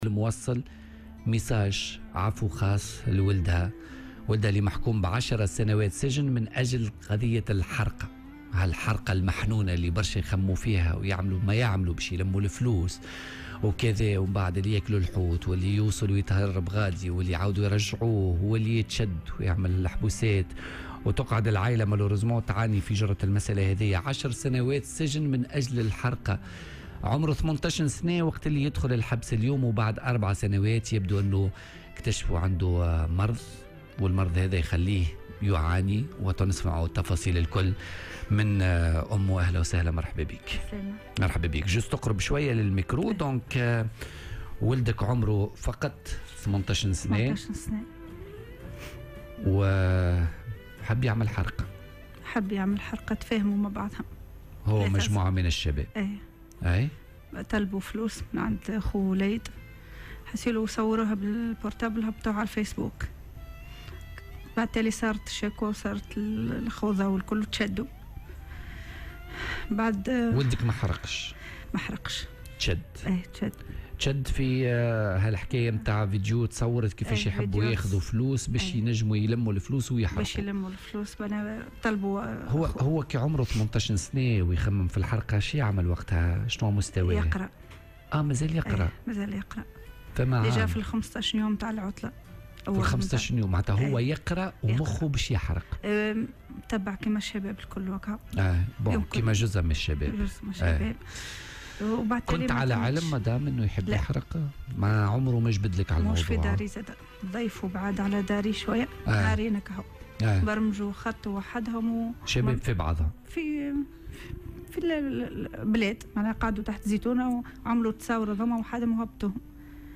توجّهت مواطنة تونسية بنداء إلى رئيس الجمهورية، قيس سعيد من أجل الإفراج عن ابنها المحكوم بـ10 سنوات سجنا بسبب التورّط في تنظيم عملية اجتياز للحدود خلسة.